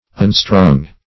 unstrung - definition of unstrung - synonyms, pronunciation, spelling from Free Dictionary